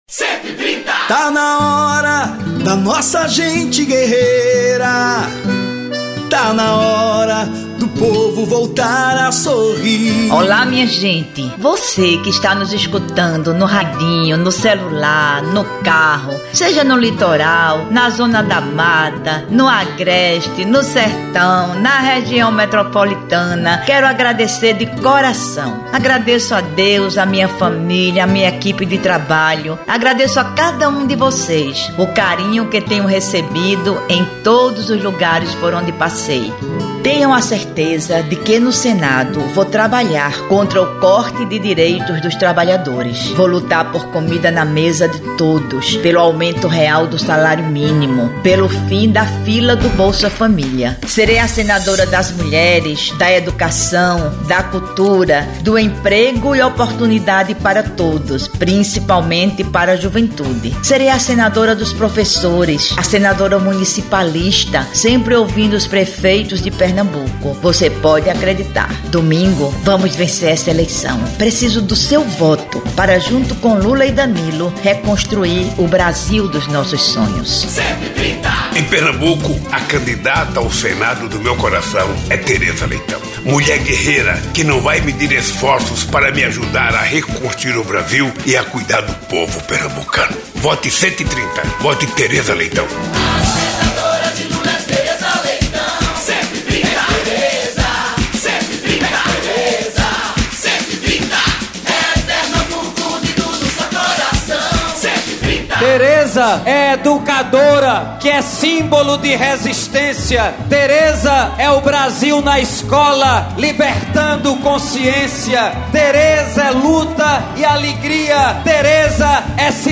No penúltimo guia eleitoral de rádio e televisão, exibido nesta quarta (28), Teresa Leitão agradece ao povo pernambucano pela boa acolhida durante toda a sua campanha, e anuncia: “serei a senadora das mulheres, da educação, da cultura, do emprego e oportunidade para todos, principalmente para juventude. Serei a senadora dos professores, a senadora municipalista”.